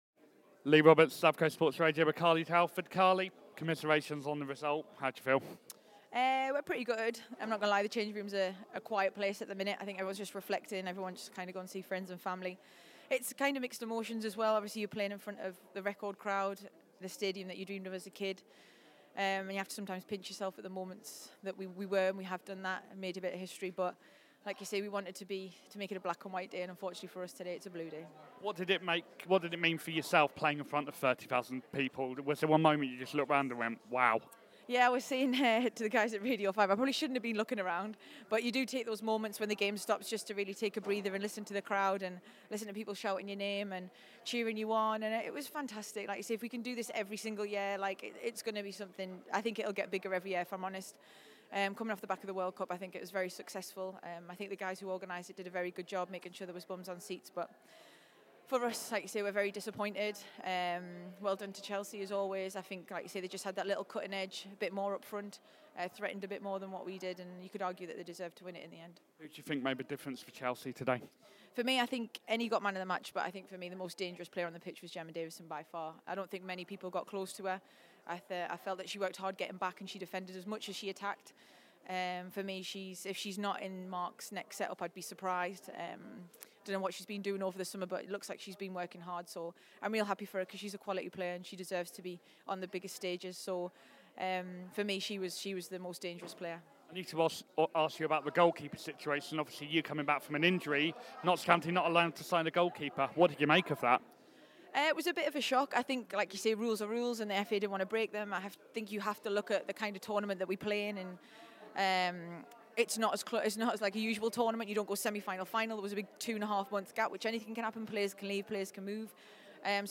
Carly Telford interview